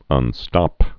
(ŭn-stŏp)